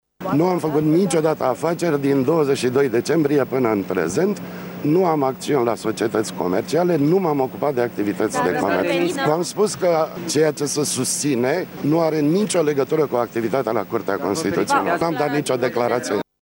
La ieşirea din sediul DNA, Toni Greblă a spus că nu are afaceri private şi că nu a dat declaraţii: